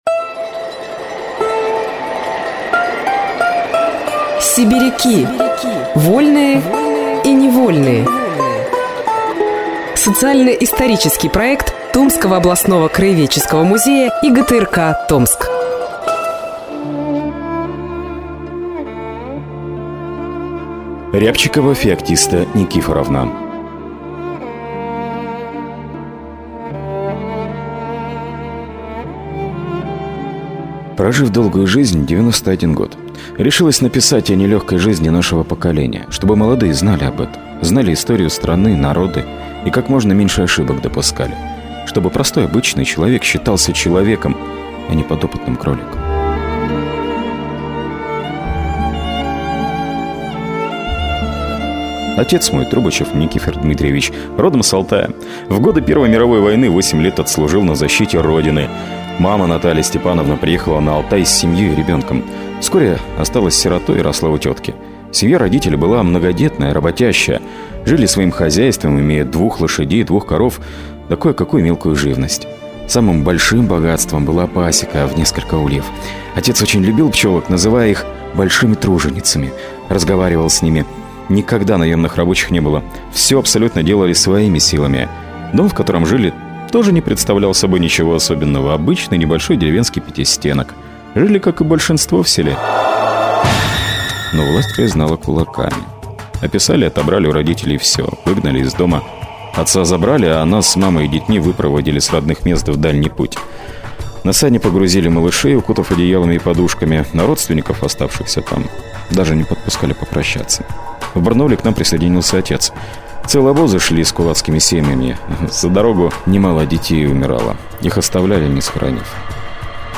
Мужской голос
Женский